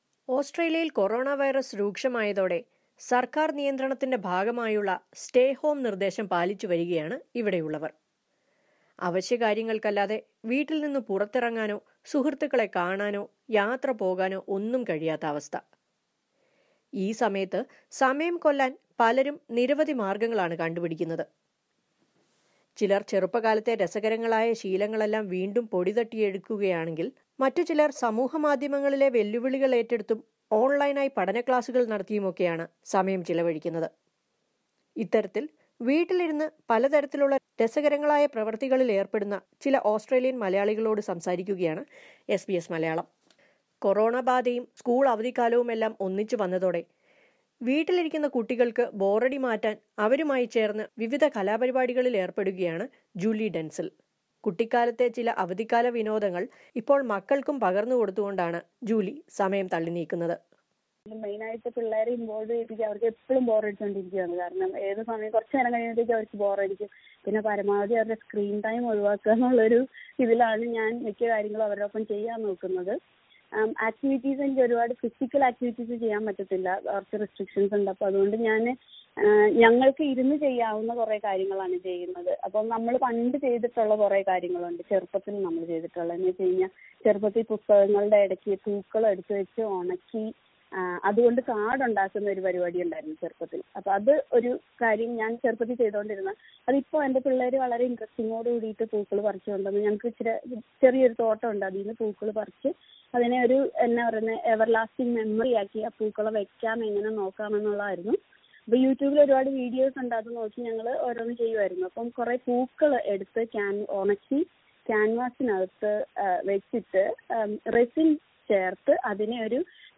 Many Australian Malayalees are spending their time at home doing various interesting activities. Listen to a report on this.